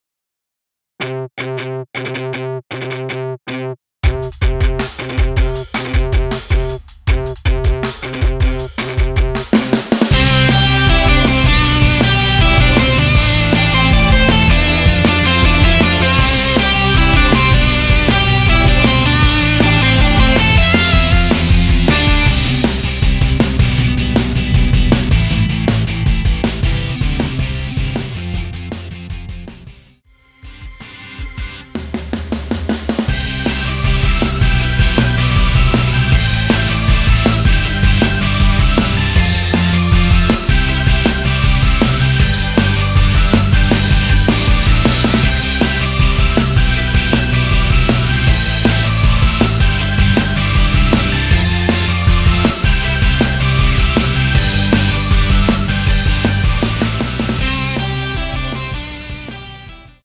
원키에서(+2)올린 MR입니다.
Fm
앞부분30초, 뒷부분30초씩 편집해서 올려 드리고 있습니다.
중간에 음이 끈어지고 다시 나오는 이유는